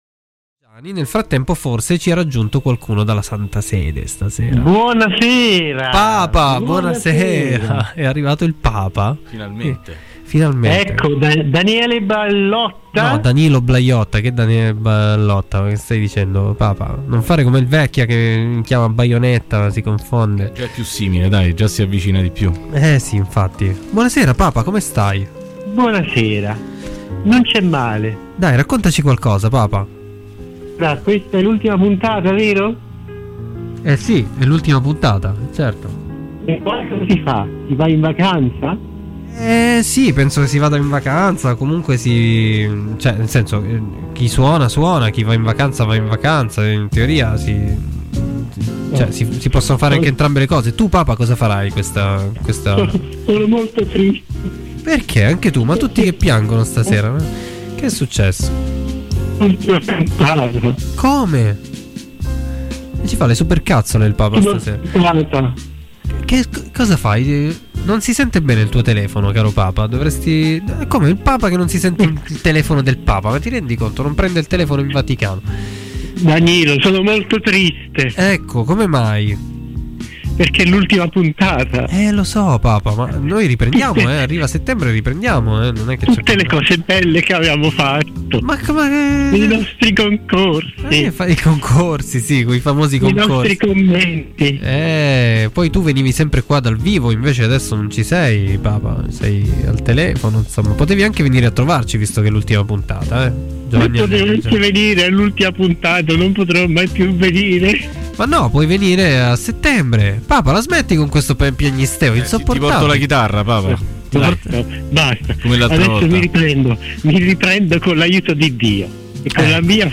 Sua Santità, il III Papa, irrompe negli studi di Radio Città Aperta, interrotto bruscamente da Maleficent!